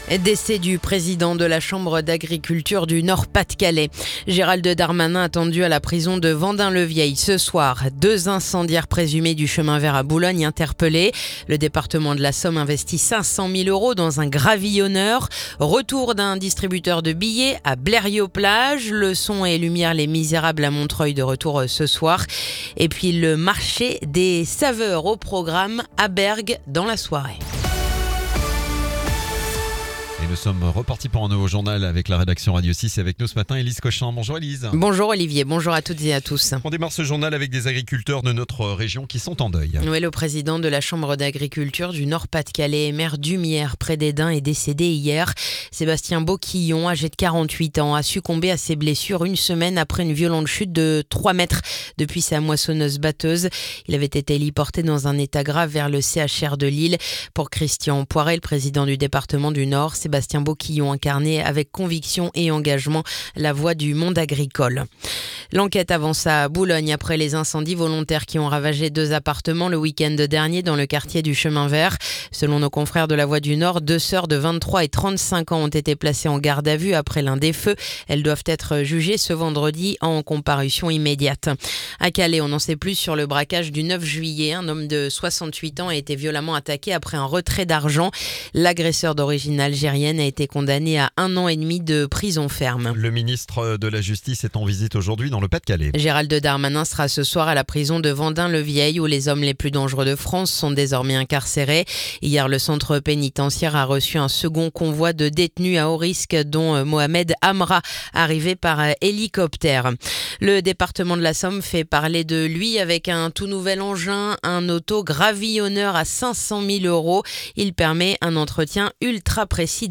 Le journal du vendredi 25 juillet